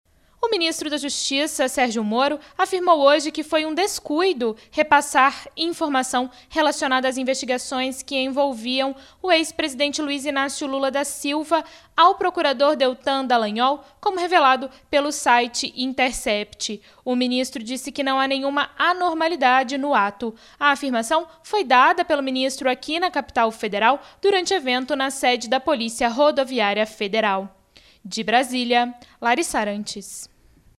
repórter
de Brasília